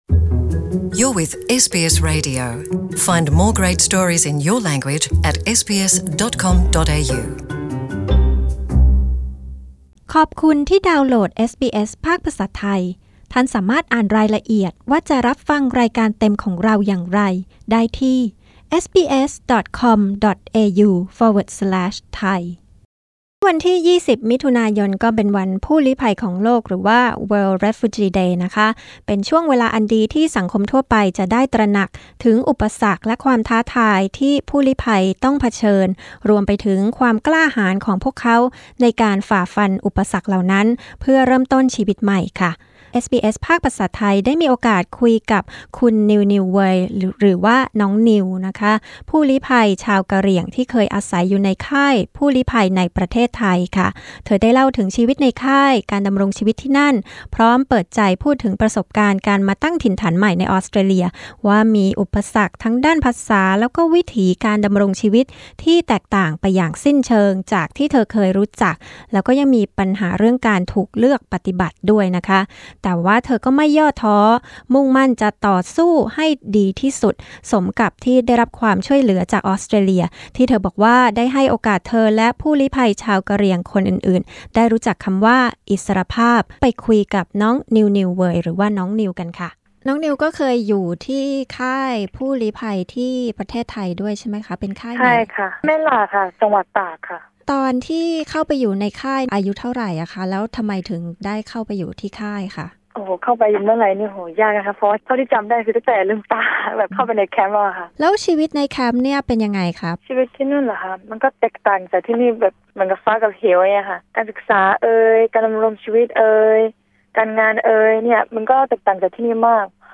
รู้จักชีวิตผู้ลี้ภัยจากสาวกะเหรี่ยงพูดไทย